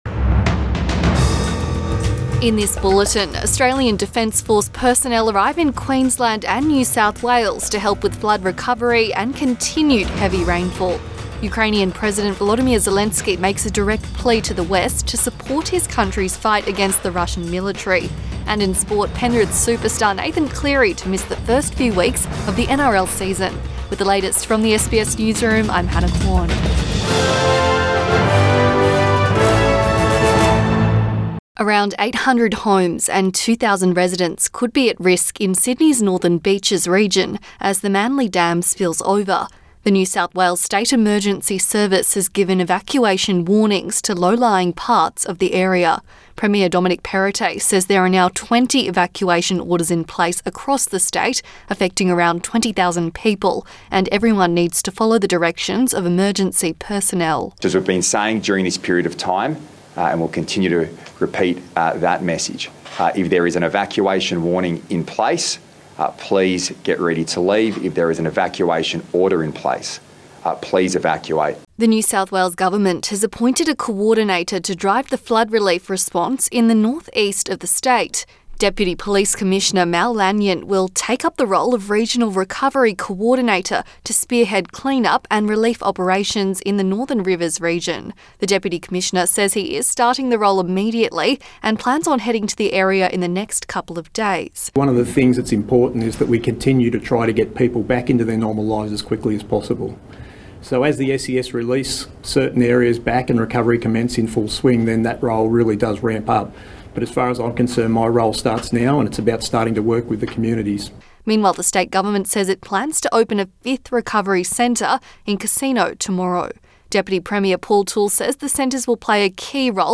PM bulletin 8 March 2022